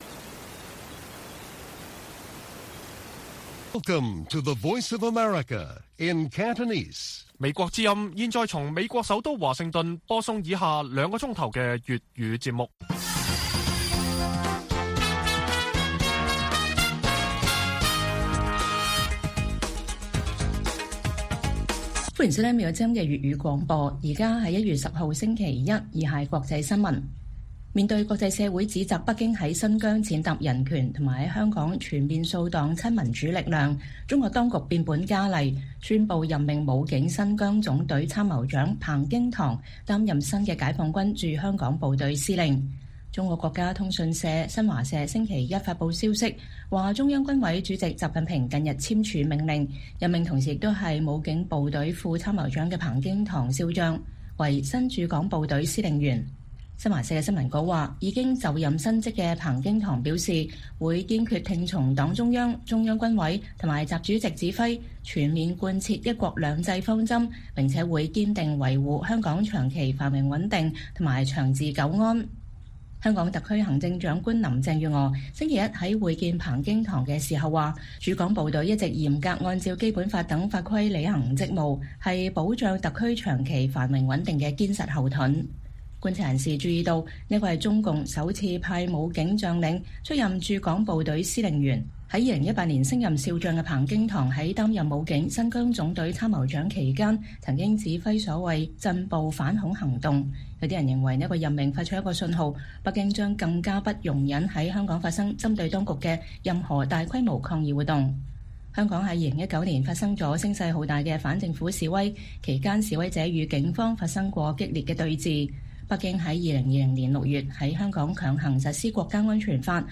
粵語新聞 晚上9-10點: 香港人英國多地示威支持新聞自由